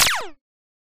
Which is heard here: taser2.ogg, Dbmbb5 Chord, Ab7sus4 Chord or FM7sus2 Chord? taser2.ogg